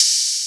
Open Hat 2 [ advanced ].wav